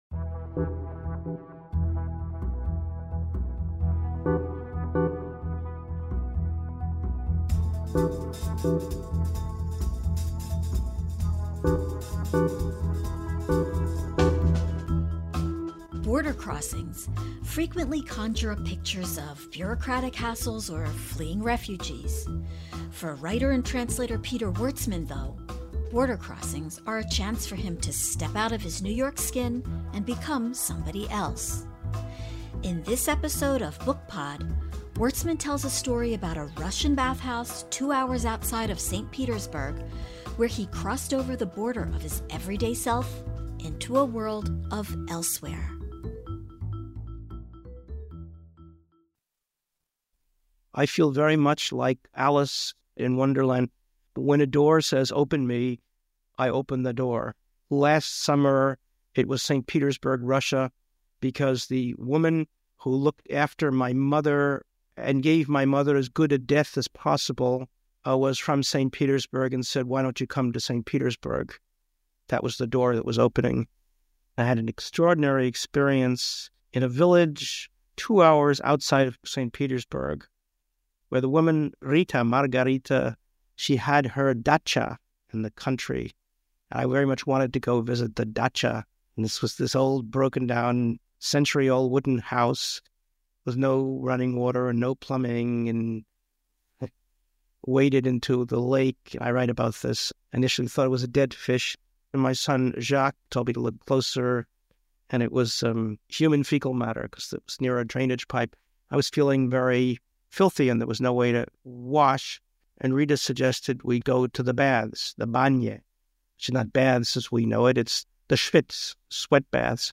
I interviewed 50+ authors, edited the interviews down to 5-8 minutes and published them online as mp3 files on my BOOKPOD website (now sunset).